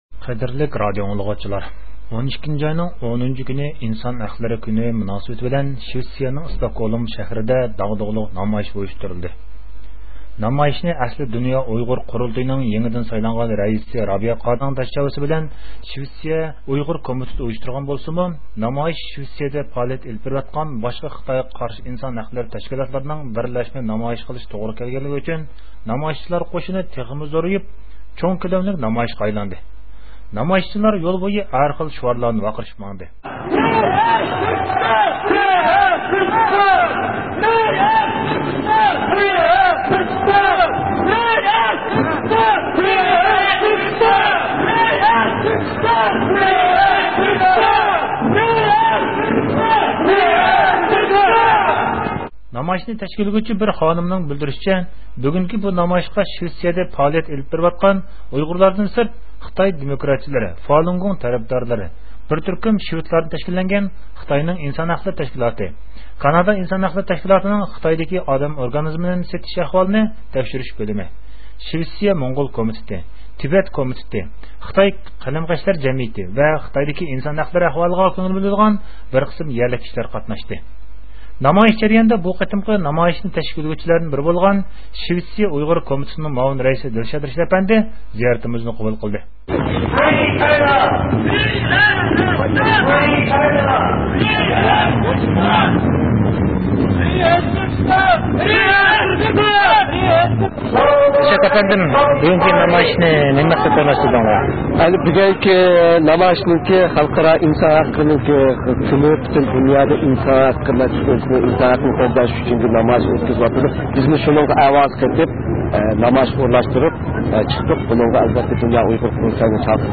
نامايىش داۋامىدا ھەر خىل شۇئارلار ئۈزۈلمەي ياڭراپ تۇردى.